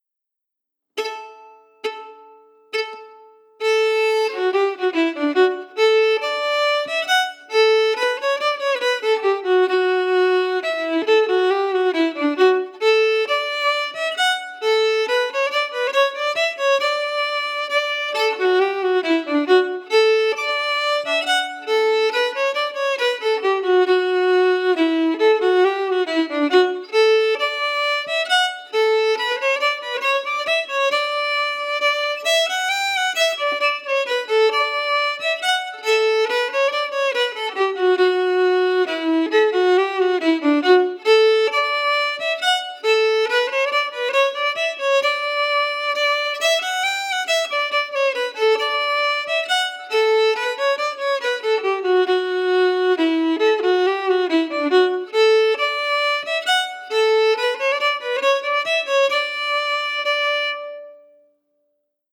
Key: D
Form: Reel/Song
Slow for learning
M: 4/4